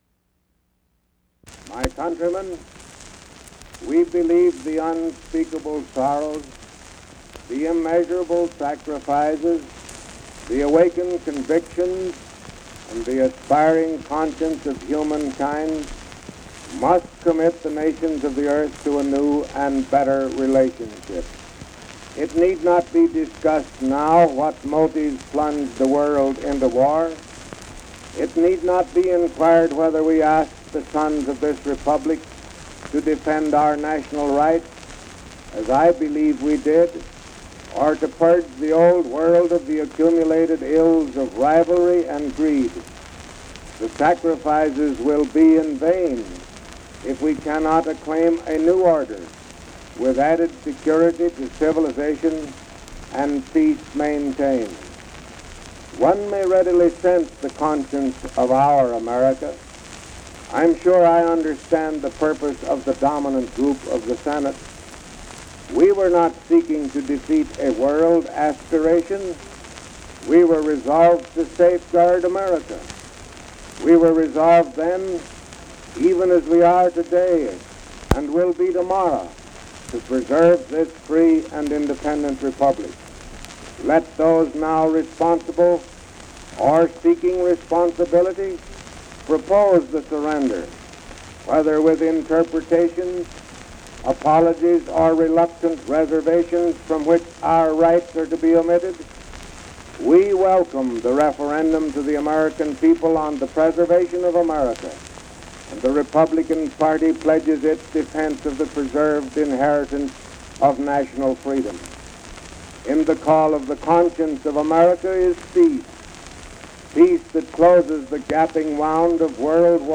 Speakers Harding, Warren G. (Warren Gamaliel), 1865-1923
Recorded by Nation's Forum, 1920.